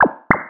《プニョッなシステム音４》フリー効果音
プッニョッとかチュッピッという感じの効果音。システム音やプッニョッとした時に。